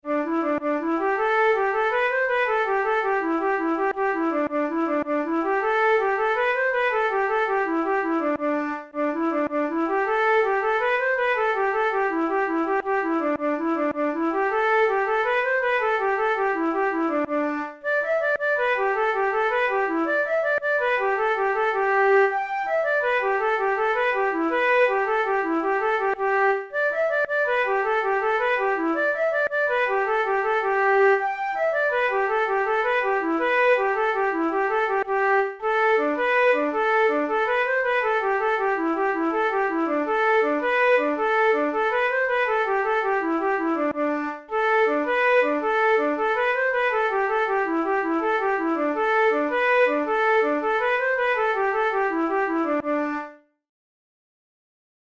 Traditional Irish jig